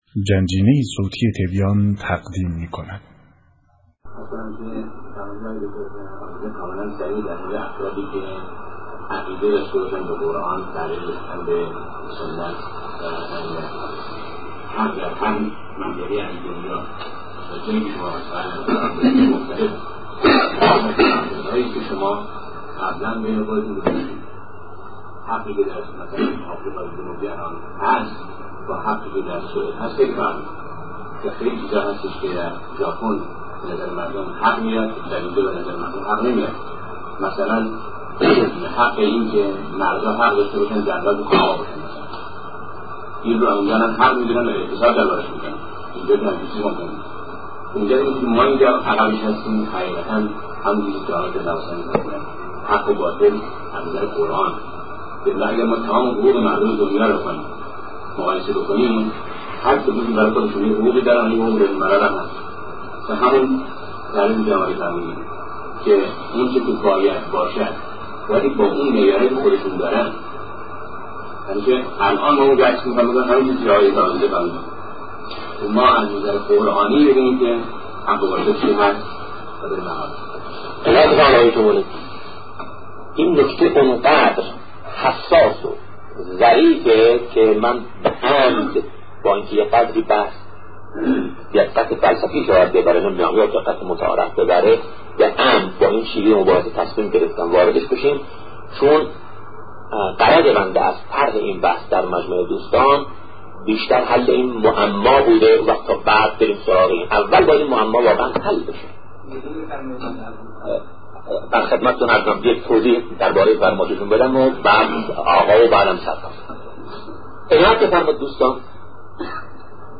سخن شهید بهشتی(ره)- در خصوص حق و باطل در قرآن- بخش‌دوم